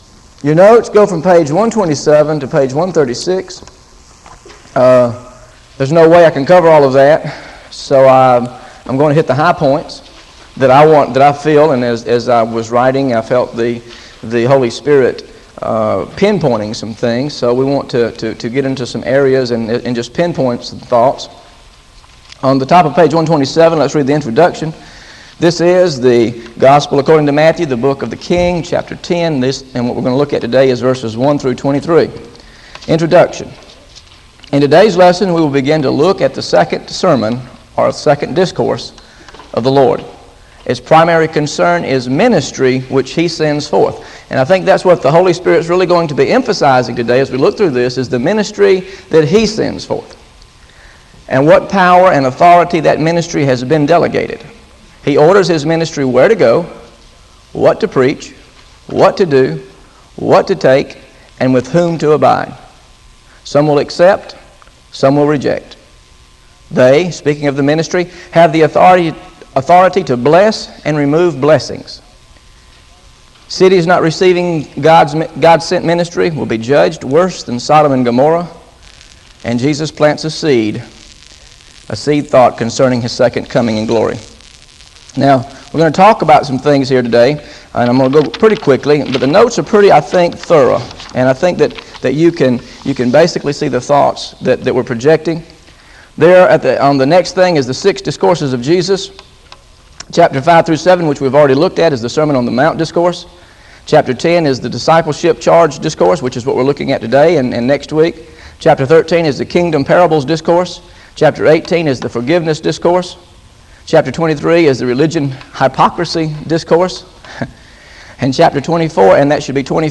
GOSPEL OF MATTHEW BIBLE STUDY SERIES This study of Matthew: Matthew 10 1-23 How to Understand God-Sent Ministry is part of a verse-by-verse teaching series through the Gospel of Matthew.